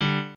piano8_4.ogg